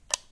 switch37.ogg